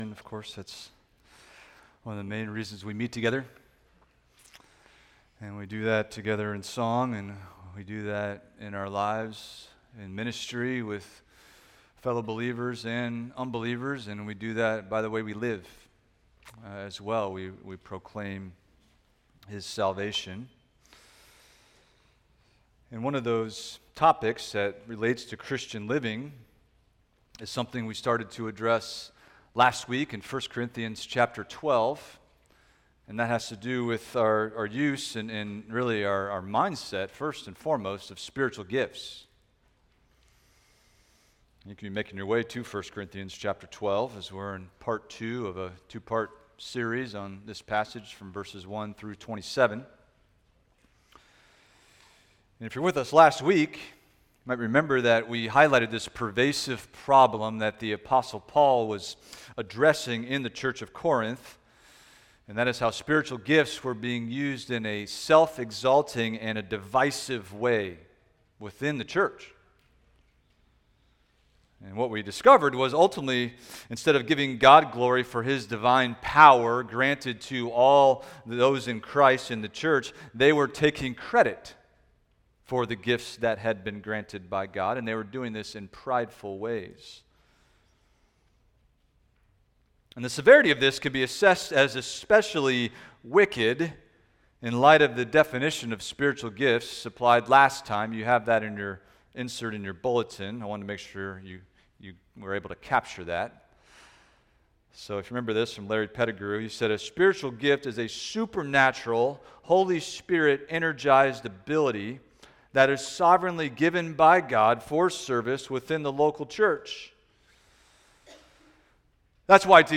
Expository Preaching from 1st Corinthians – A Christ-Exalting Understanding of the Spirit’s Activity in the Church – Part 2 (with Baptism following on video only)